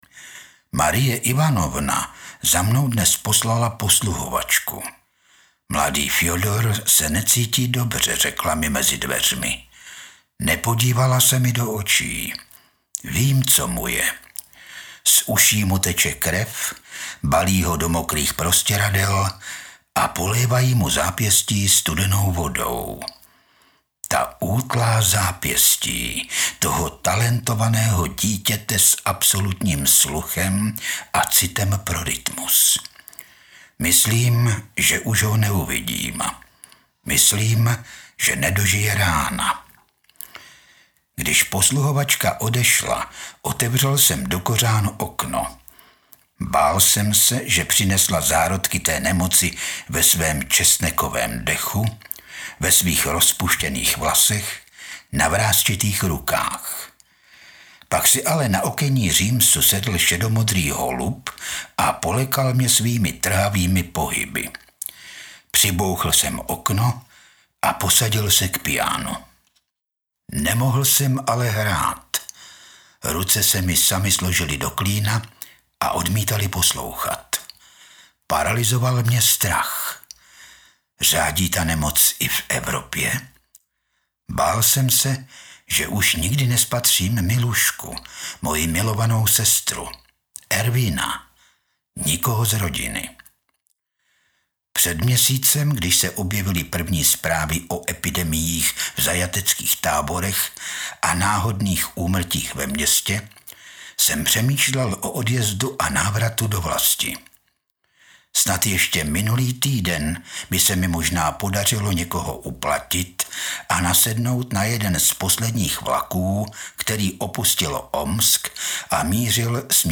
stáhnout ukázku
Audiokniha